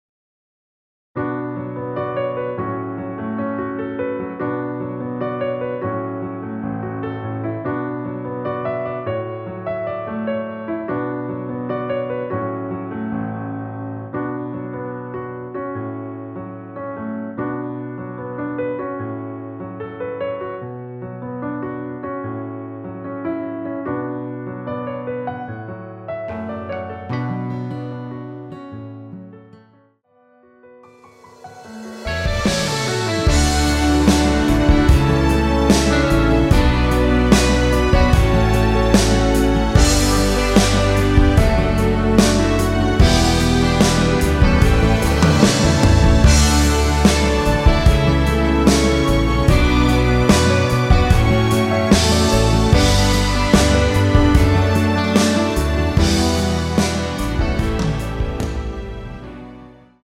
Bm
앞부분30초, 뒷부분30초씩 편집해서 올려 드리고 있습니다.
중간에 음이 끈어지고 다시 나오는 이유는